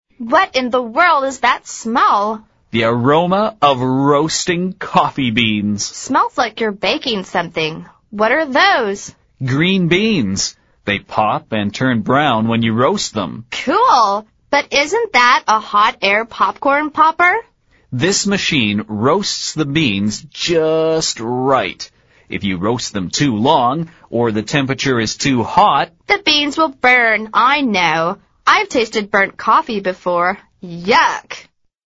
美语会话实录第97期(MP3+文本):Roast coffee beans